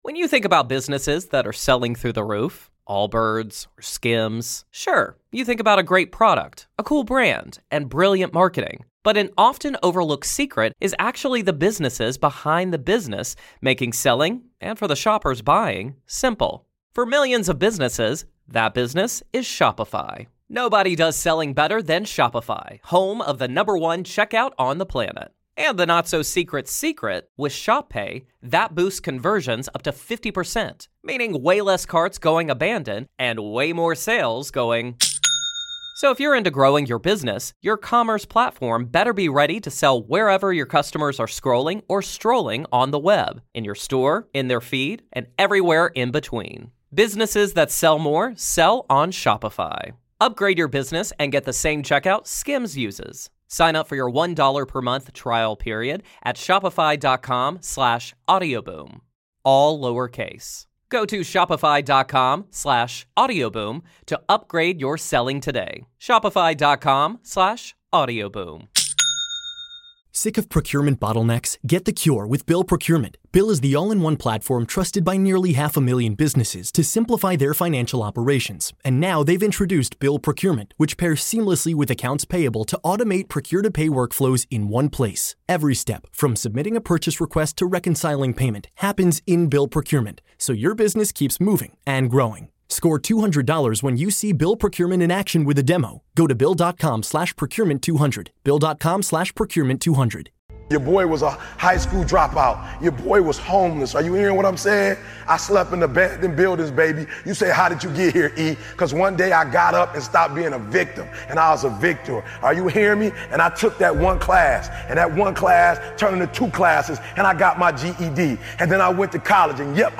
Speaker: Eric Thomas